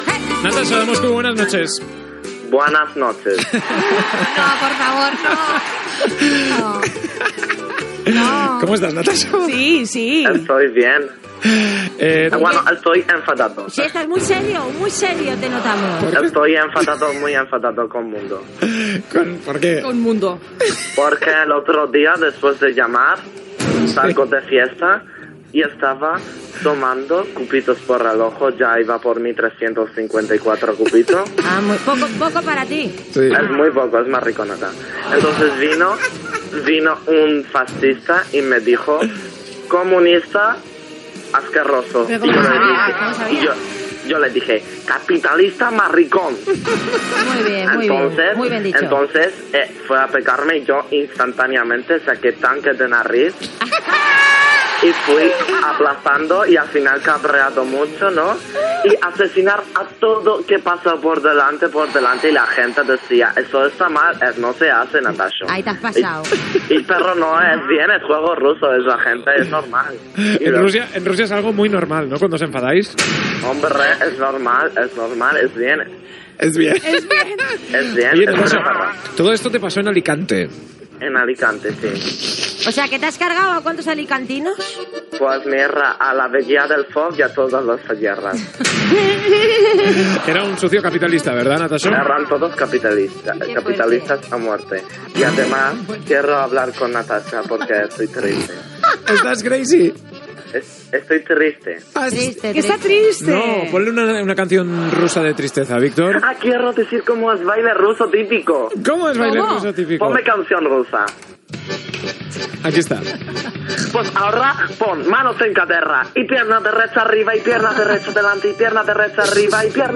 Diàleg humorístic entre Natasho i Natasha
Entreteniment